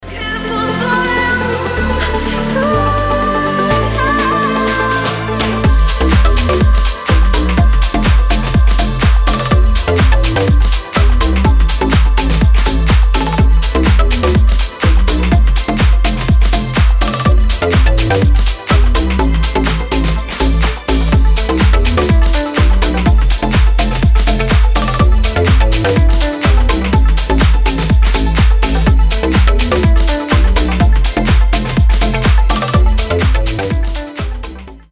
• Качество: 320, Stereo
deep house
восточные мотивы
женский голос
dance
Club House